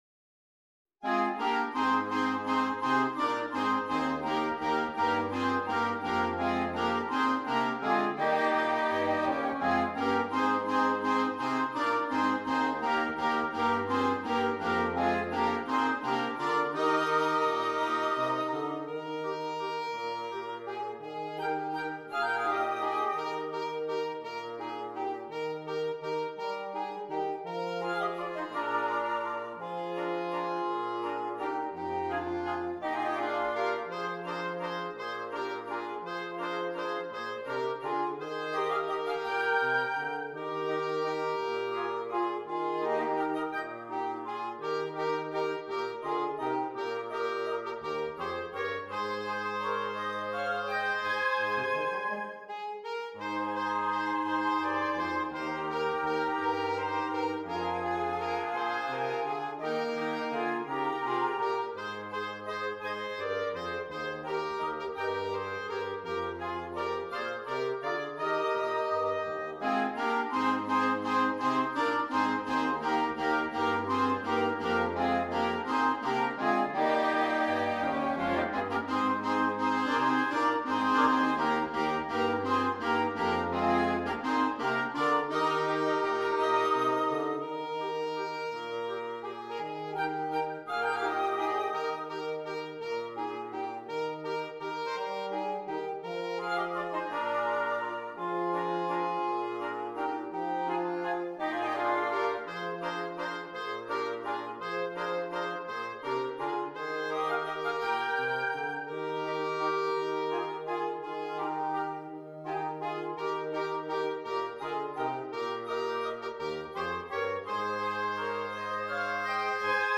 Interchangeable Woodwind Ensemble
Traditional